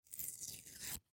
10. Наклейку аккуратно отклеили